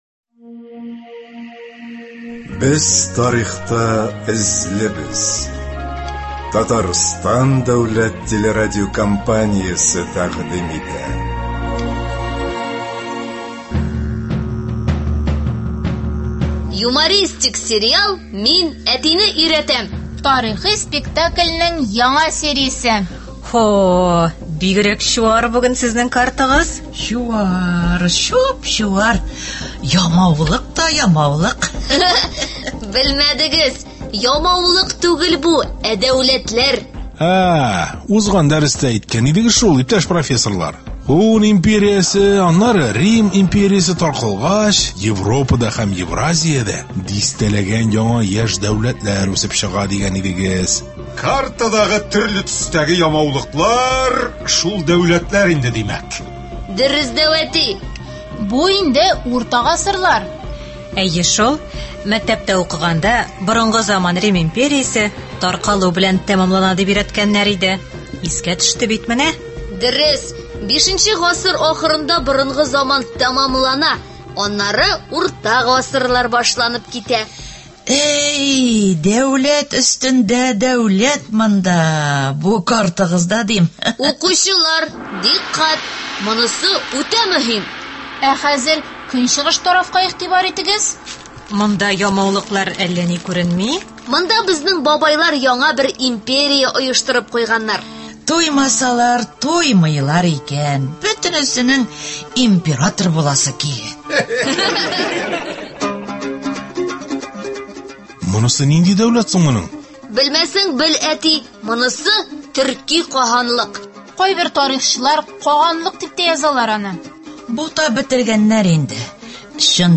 Радиосериал.